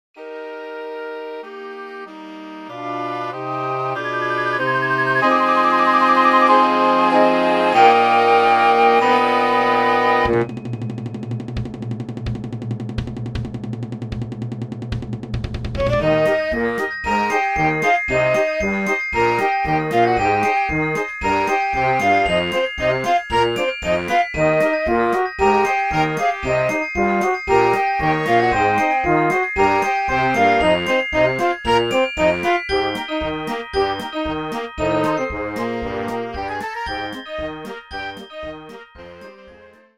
Orkiestrowa